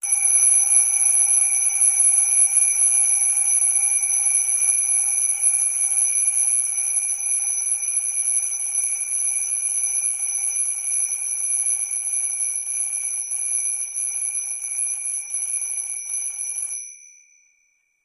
Alarm Clock, Wind - Up, High Pitched, Buzzy, Very Fast Ringing.